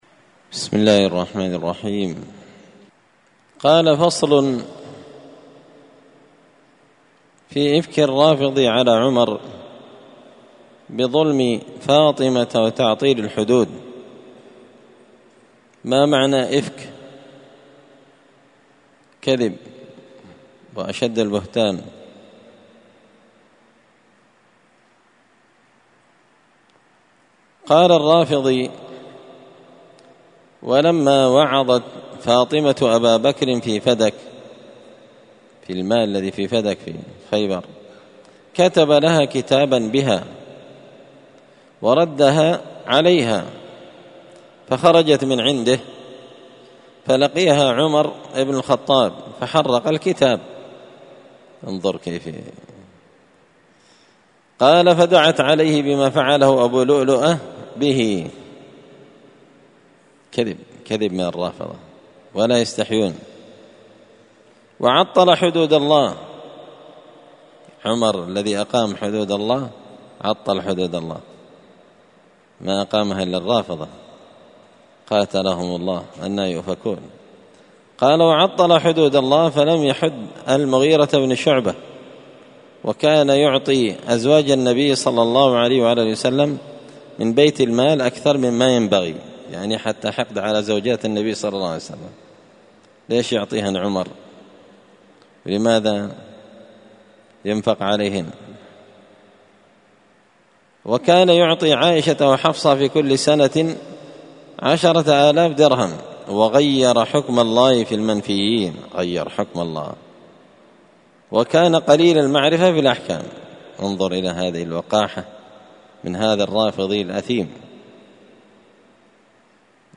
الدرس السادس عشر بعد المائة (116) فصل في إفك الرافضي على عمر بظلم فاطمة وتعطيل الحدود